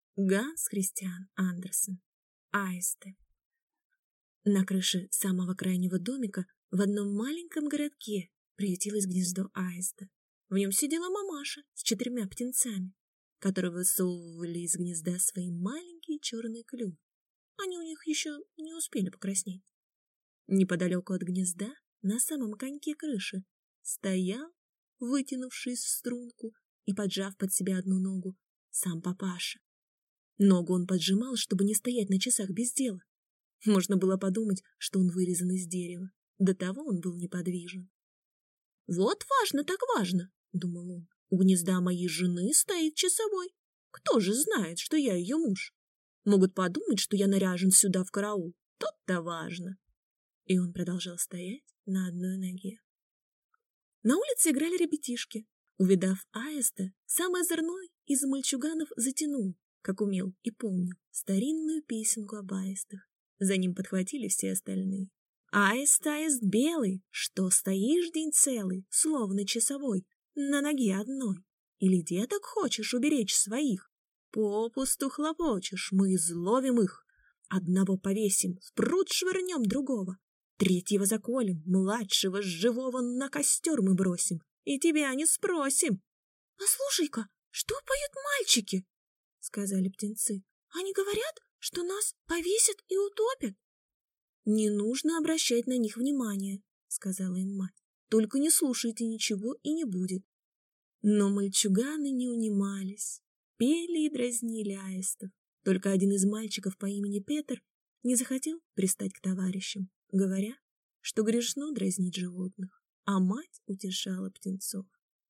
Аудиокнига Аисты | Библиотека аудиокниг